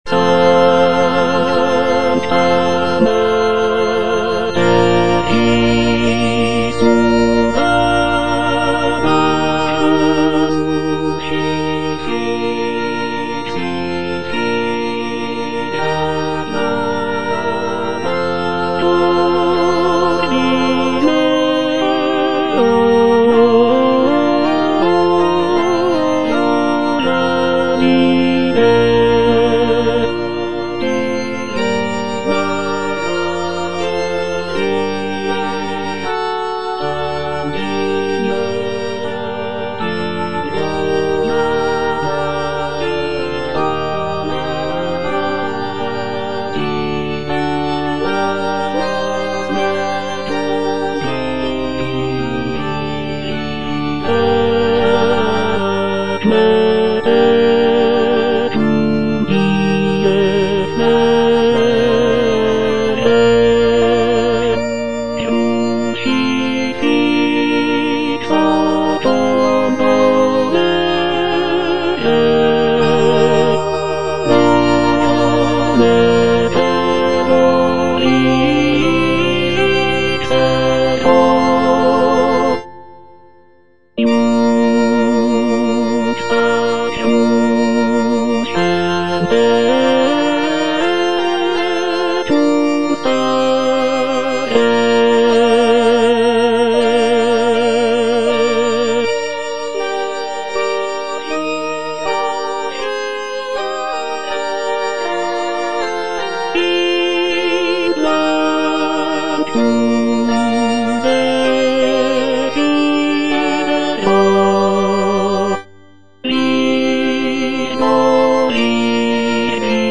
G.P. DA PALESTRINA - STABAT MATER Sancta Mater, istud agas (tenor II) (Emphasised voice and other voices) Ads stop: auto-stop